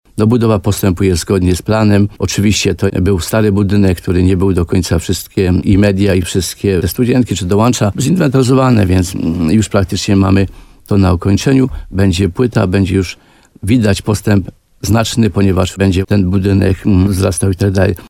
31starosta.mp3